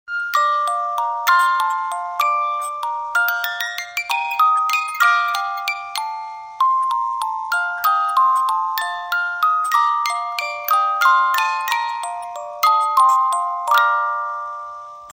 Cover , Romántico